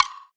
Perc [Wood].wav